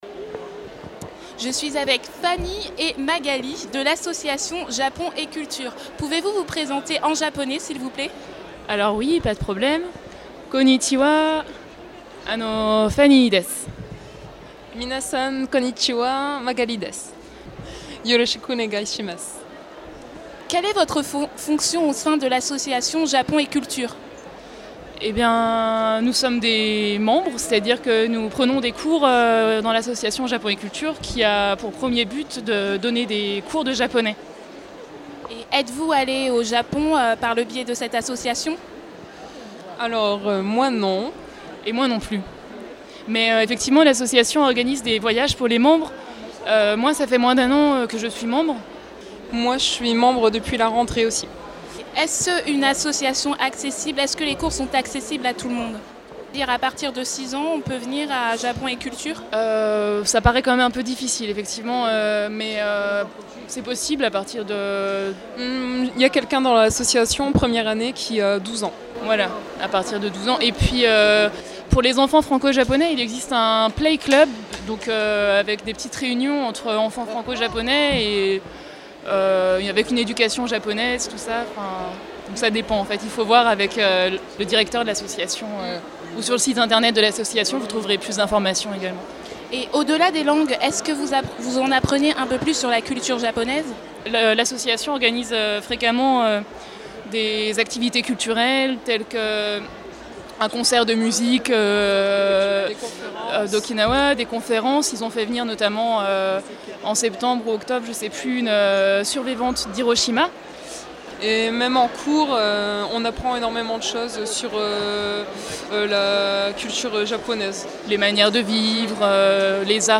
à la CCI de Lille
Interviews réalisées pour Radio Campus